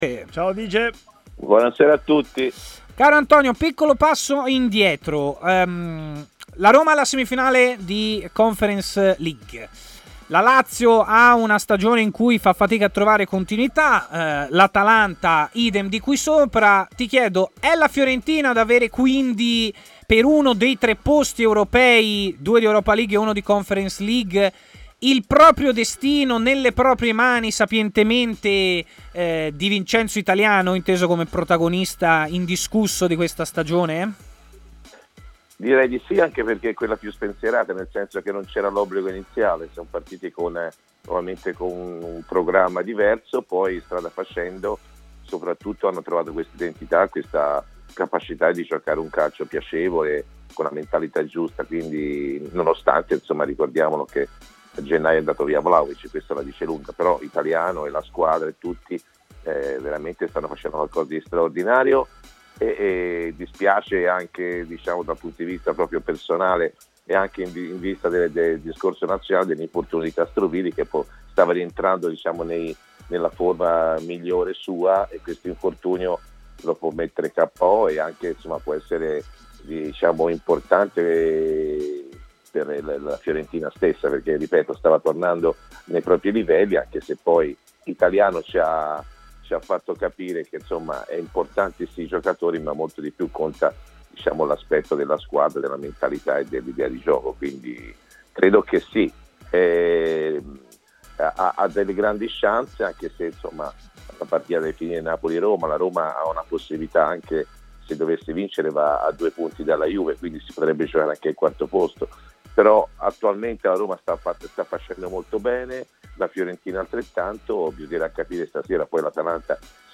trasmissione di TMW Radio